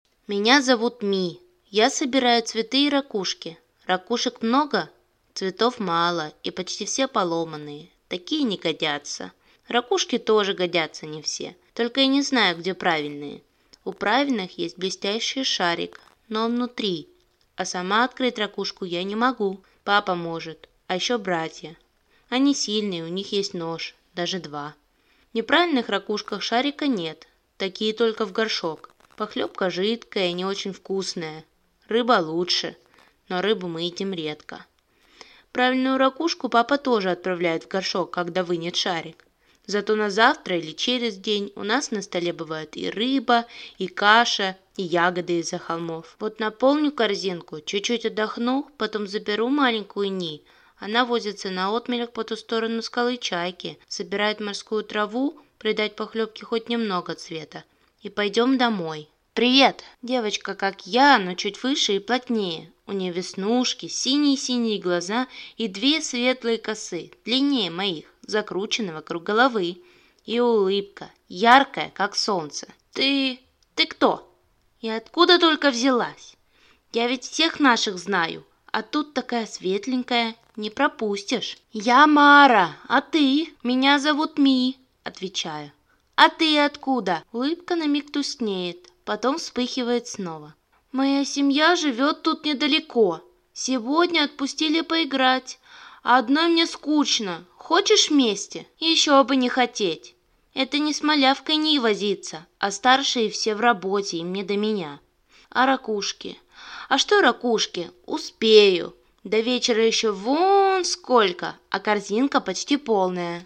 Аудиокнига Мара | Библиотека аудиокниг